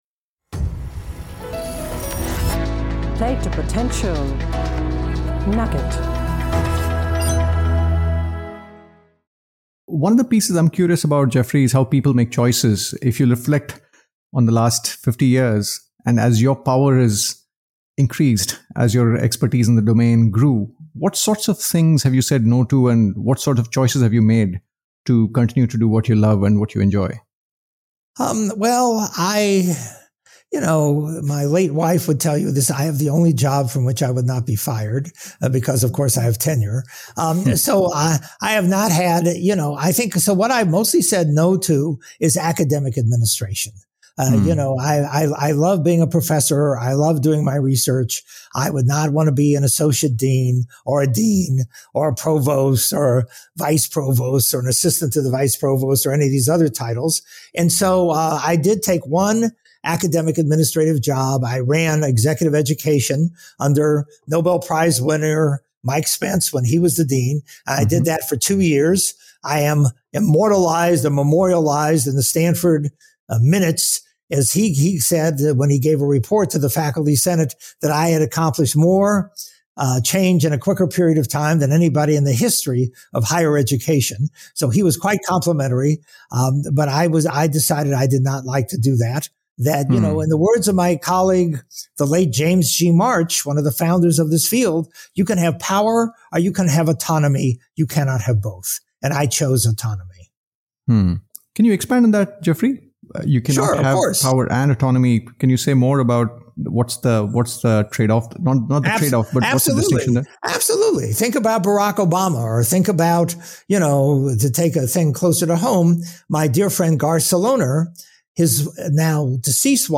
ABOUT THE PODCAST Play to Potential podcast started in Dec 2016 and features conversations around three broad themes - Leadership, Transitions and Careers.